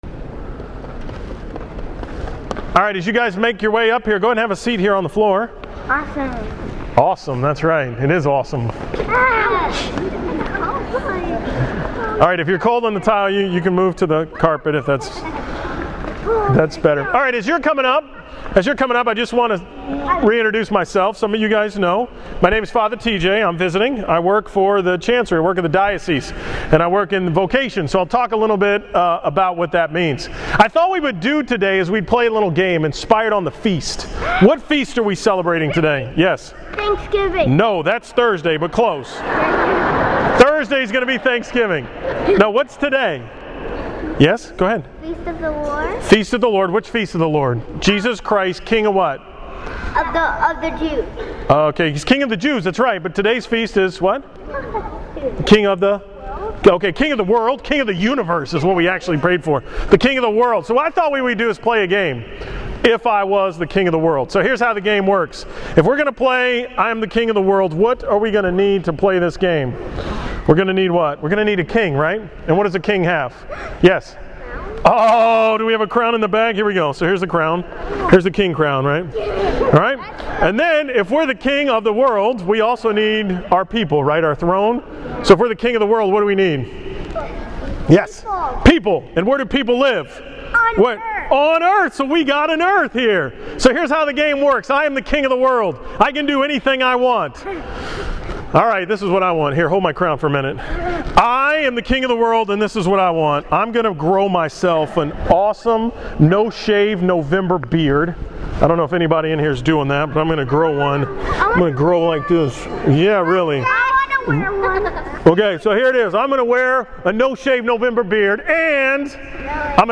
From the 11 am Mass at St. Ignatius on November 23, 2014